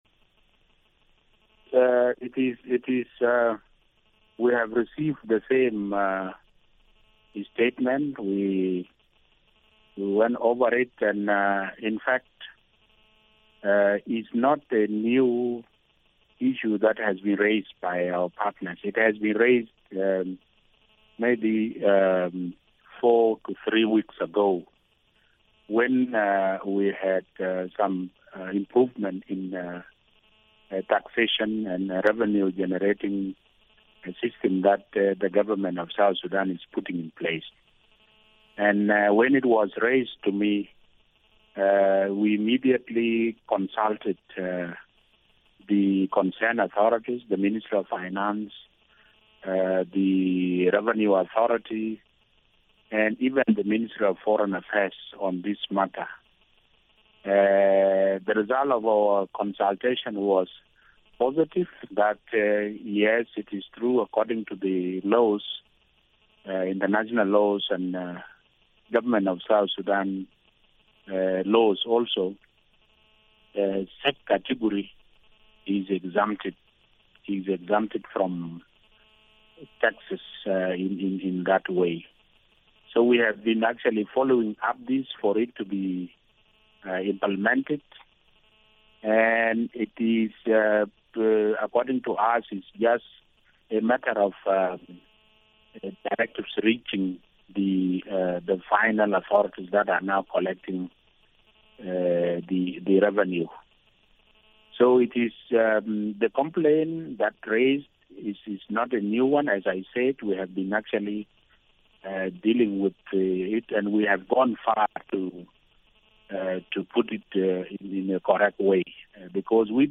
Headliner Embed Embed code See more options Share Facebook X Subscribe Guest: Minister of Humanitarian Affairs - Albino Atak Share Facebook X Subscribe Next MBS.